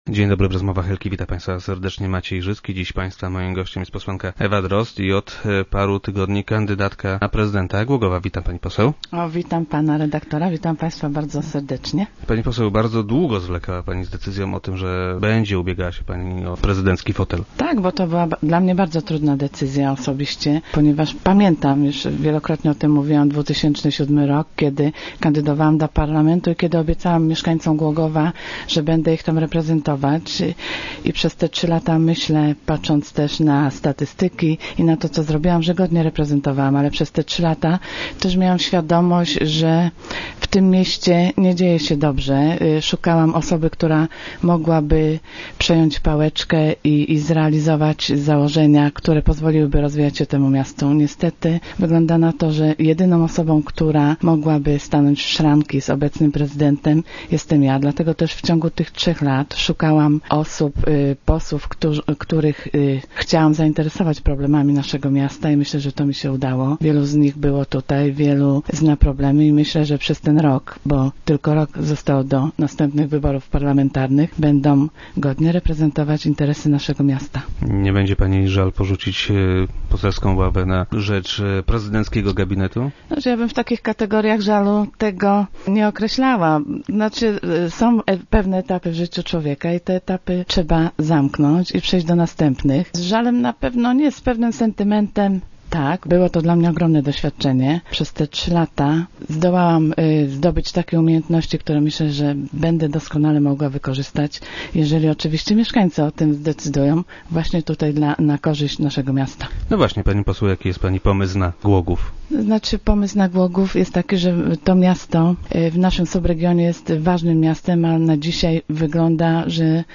- Głogów jest ważnym miastem w regionie, a tego nie widać. Trzeba to zmienić - twierdzi parlamentarzystka, która była dziś gościem Rozmów Elki.
- Wszyscy, którzy przyjeżdżają do Głogowa, dopiero na miejscu widzą jakie walory ma to miasto. Brakuje promocji Głogowa. Rozwija się ono tylko na niewielu płaszczyznach. Myślę, że jest to bolączka, nad którą należy popracować - mówiła na radiowej antenie kandydatka Platformy Obywatelskiej na prezydenta Głogowa.